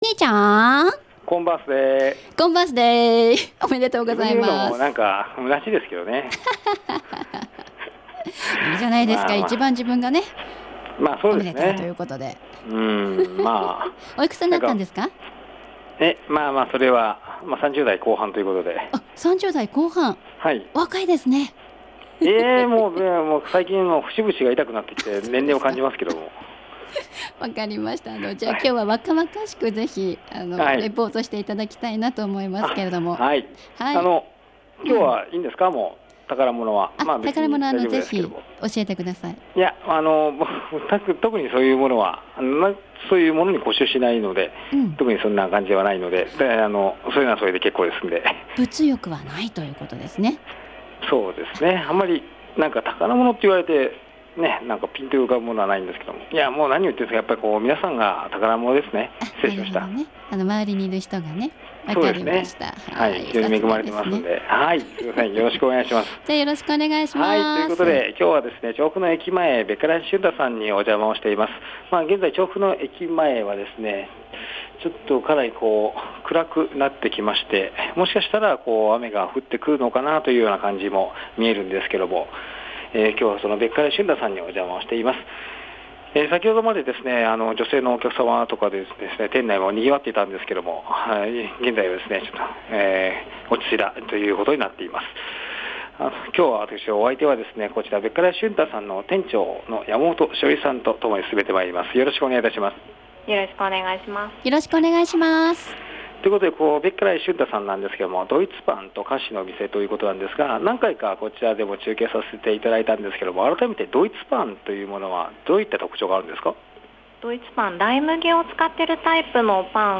今日は調布駅前に出没しました。
ちょうど中継終了後すぐに届けられていました。